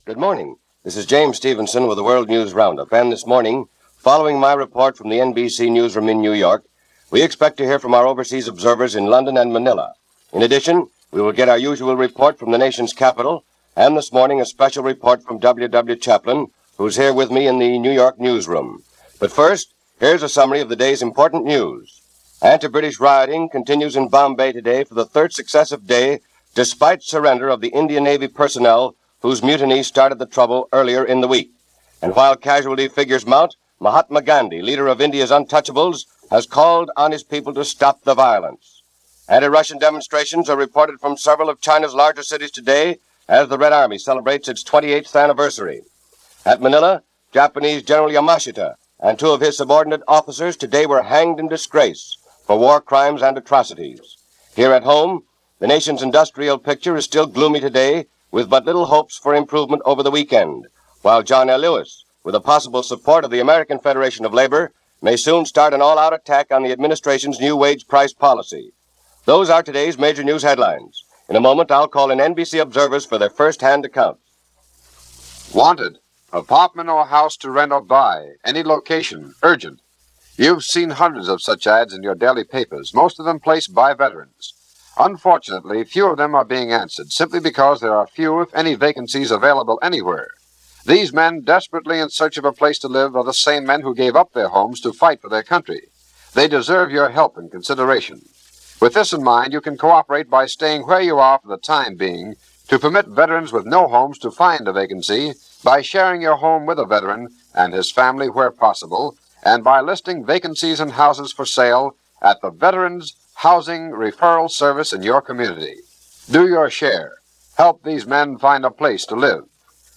February 23, 1946 - Riots In Bombaby - An Execution In Manila - Argentina Goes to the Polls - News for this day from NBC World New Roundup